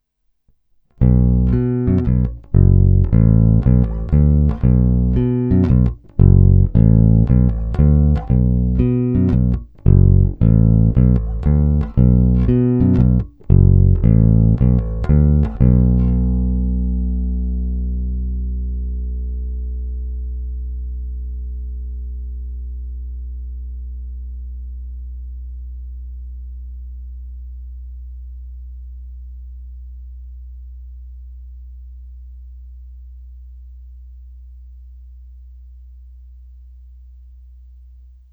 Zvuk je naprosto klasický Jazz Bass, zvláště v pasívním režimu.
Zvuk je obecně poměrně ostrý, agresívní, naštěstí není problém korekcemi výšek umravnit, nebo to pak lze samozřejmě řešit na aparátu.
Není-li uvedeno jinak, následující nahrávky jsou provedeny rovnou do zvukové karty, jen normalizovány, jinak ponechány bez úprav.
Snímač u krku